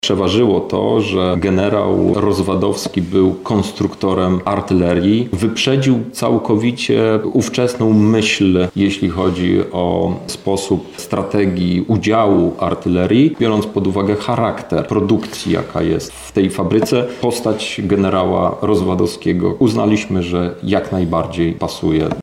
– tłumaczy Europoseł Krzysztof Hetman.